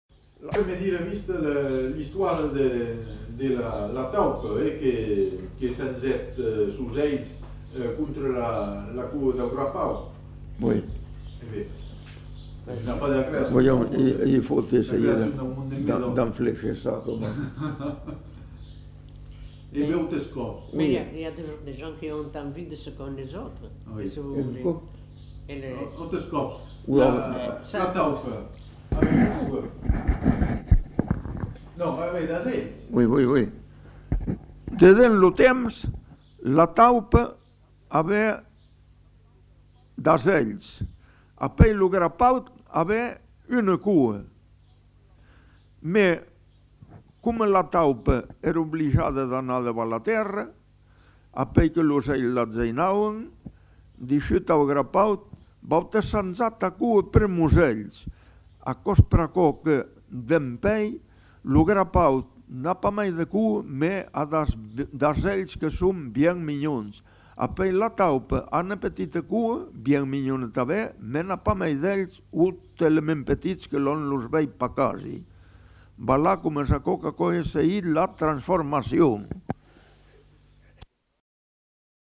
Lieu : Saint-Yzans-de-Médoc
Genre : conte-légende-récit
Type de voix : voix d'homme
Production du son : parlé